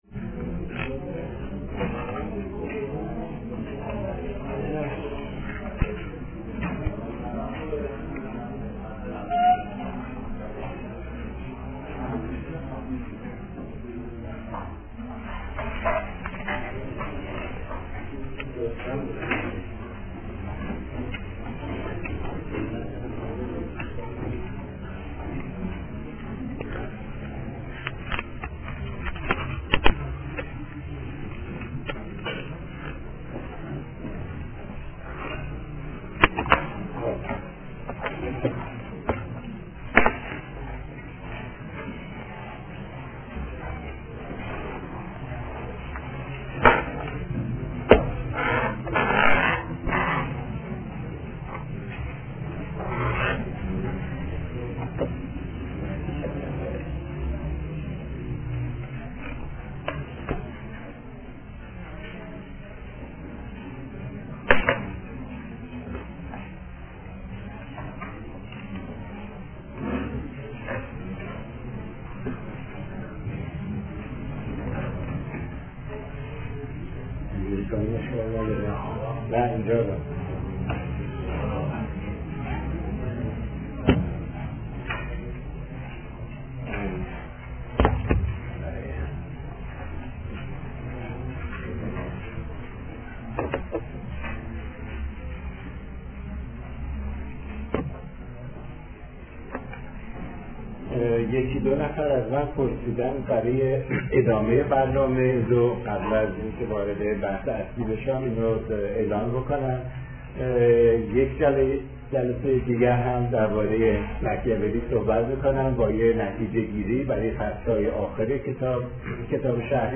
فرهنگ امروز: فایل حاضر قسمت چهارم درس‌گفتار های ماکیاوللی است که «سیدجواد طباطبایی» سال‌ها پیش آن را تدریس کرده است.